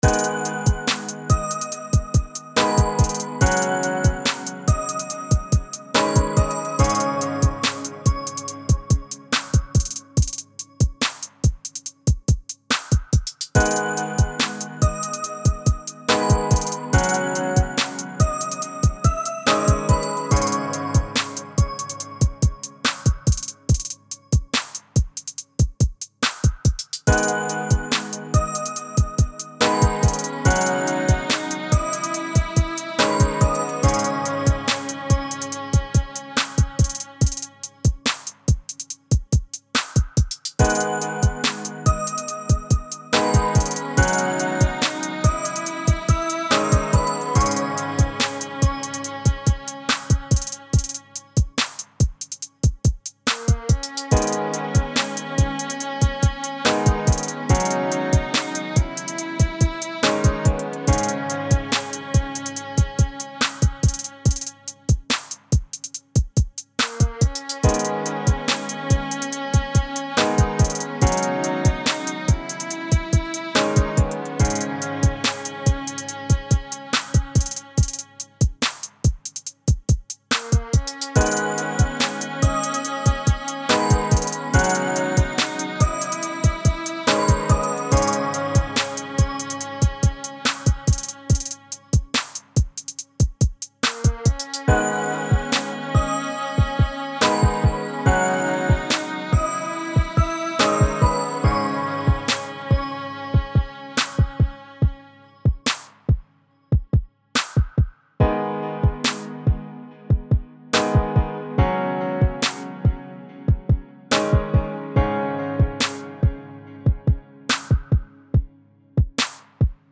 Deep Thoughts [Trap HipHop - No vocal]
trap no_vocal beat
the Trap element of the closed Hi-Hat